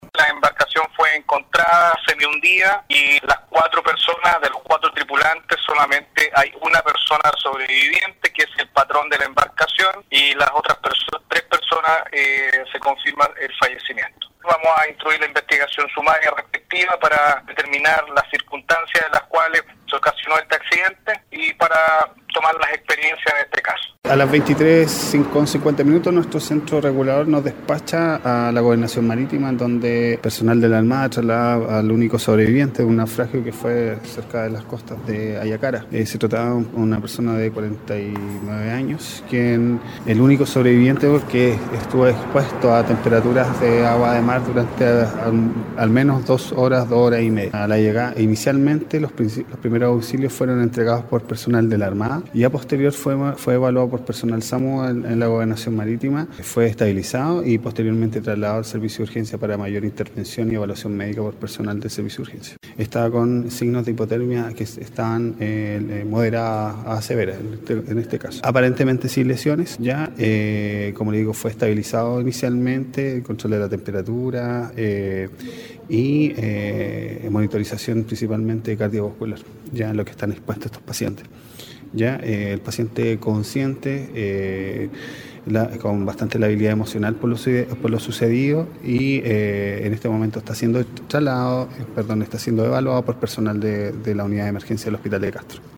A la zona concurrieron lanchas patrulleras de la Armada y embarcaciones civiles que dieron con la lancha semihundida por el fuerte oleaje, indicó el gobernador marítimo de Castro, Héctor Aravena.